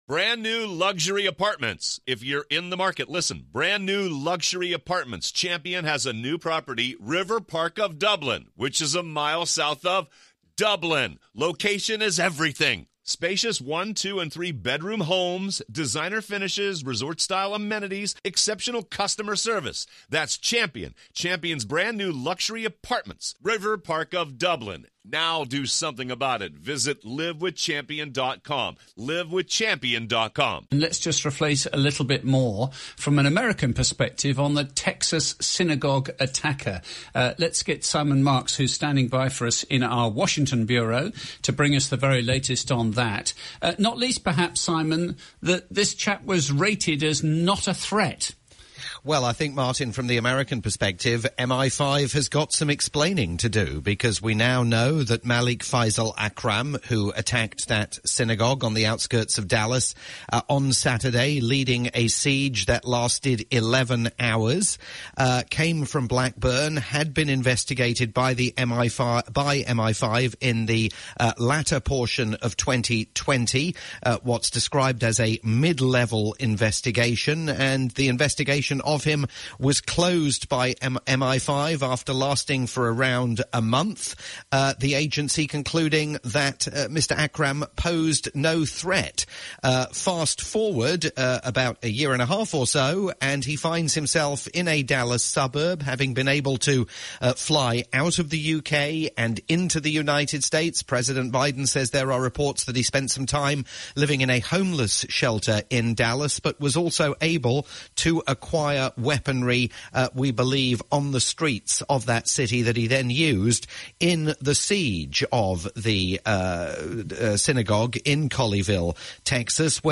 lunchtime round up